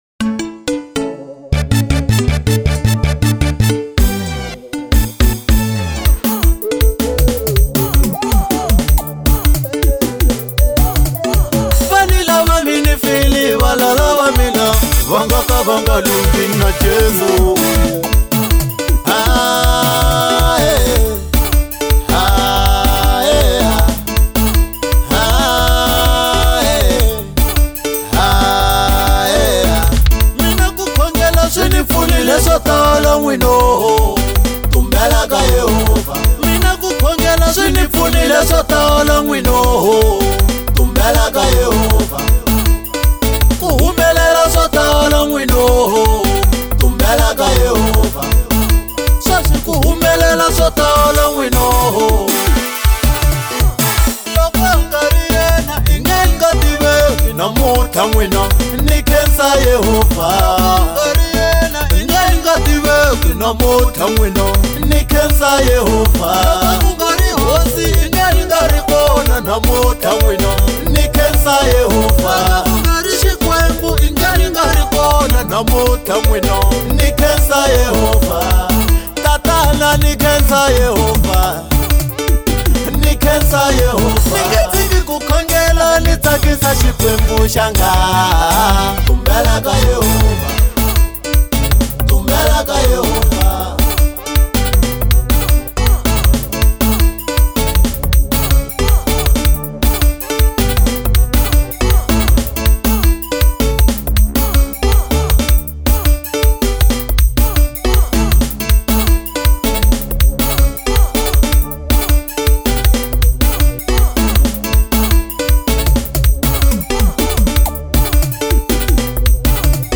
05:01 Genre : Xitsonga Size